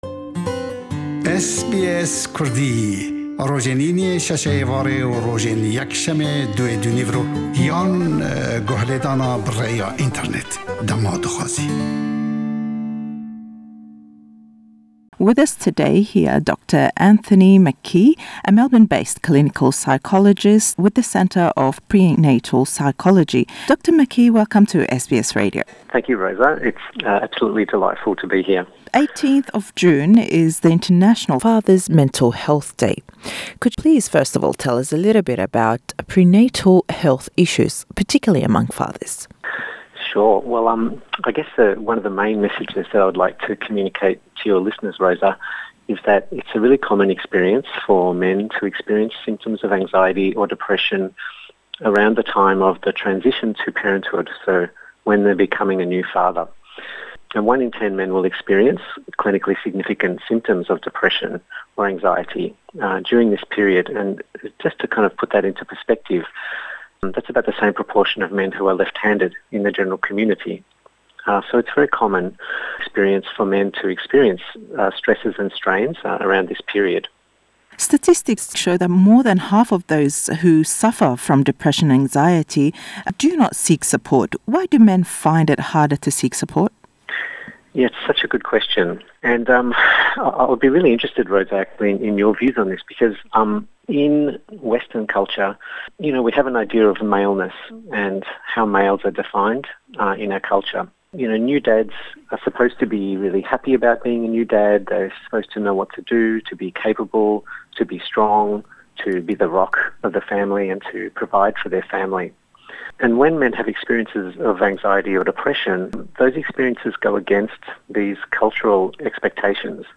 Le em lêdwane da le gell Derûnnas (Clinical Psychologist)